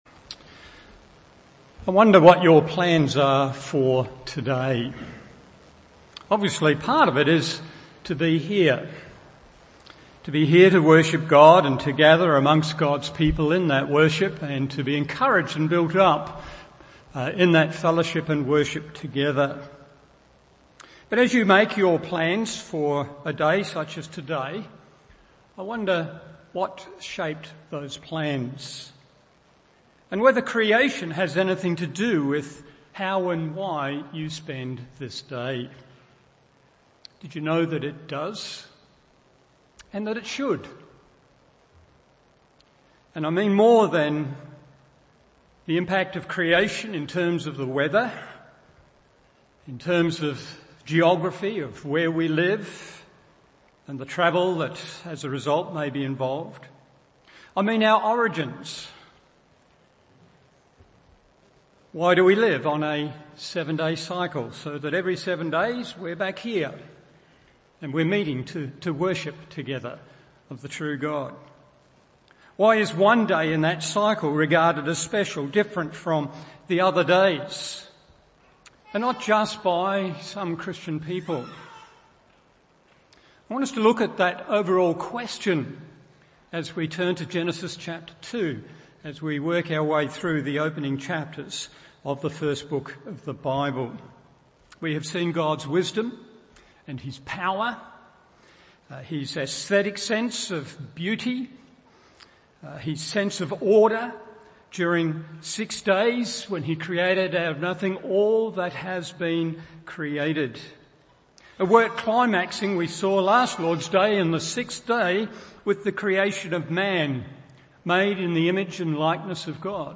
Morning Service Genesis 2:1-3 1.